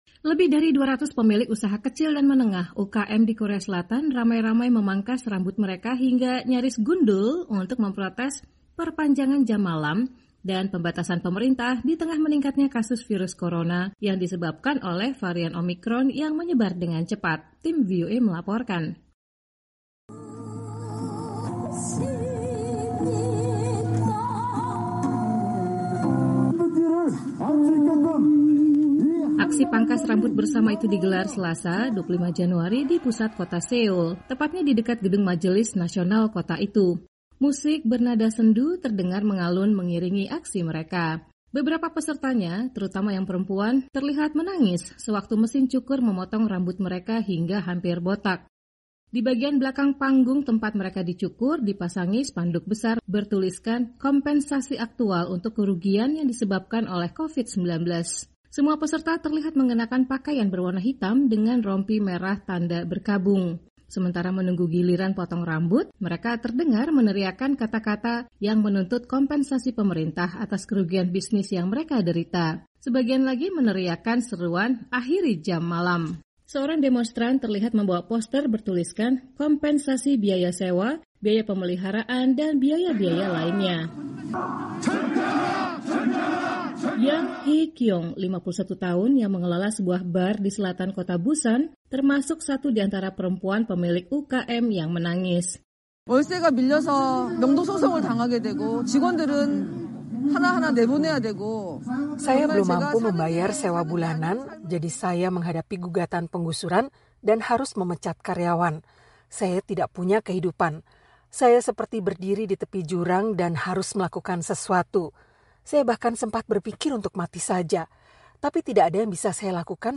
Musik bernada sendu terdengar mengalun mengiringi aksi mereka.
Sementara menunggu giliran potong rambut, mereka terdengar meneriakkan kata-kata yang menuntut kompensasi pemerintah atas kerugian bisnis yang mereka derita. Sebagian lagi meneriakkan seruan “Akhiri jam malam.”